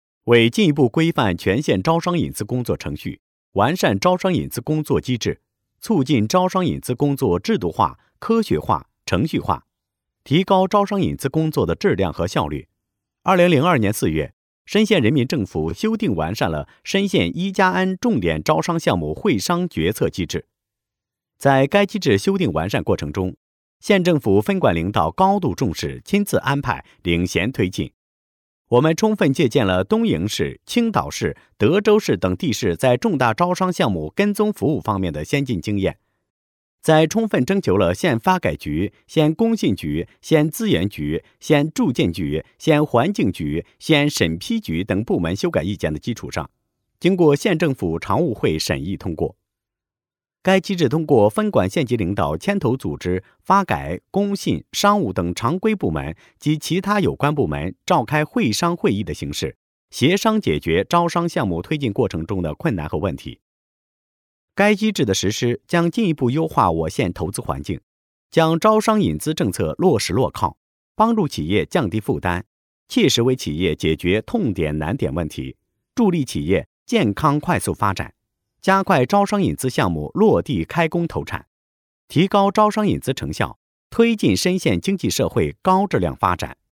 专家语音解读《莘县“1+N”重点招商项目会商决策机制》
专家介绍:常现雨 莘县商务和投资促进局党组书记、局长。